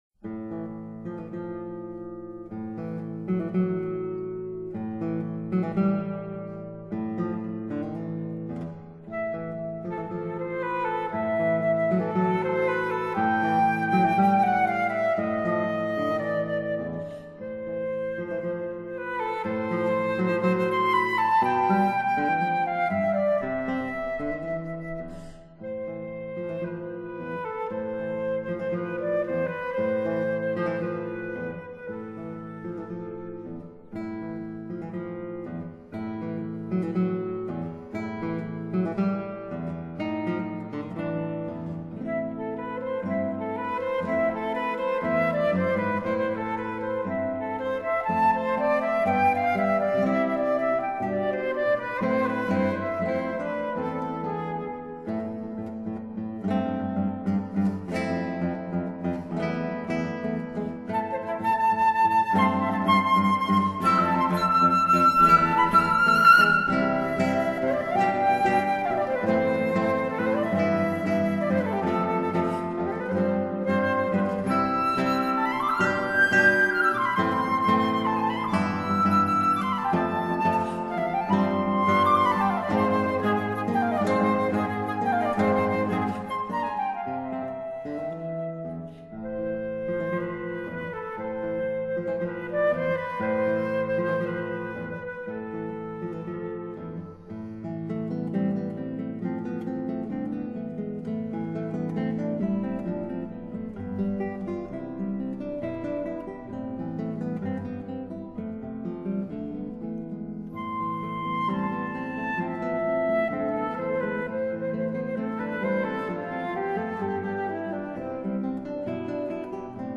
( 長笛與吉他 幻想曲 )
Classical/Instrumental | 01:00:58
滿賦天質的雙人女子組合，精致典雅的演奏！
長笛與吉他的二重奏